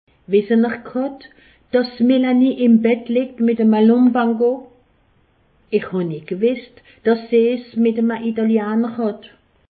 Haut Rhin
Ville Prononciation 68
Pfastatt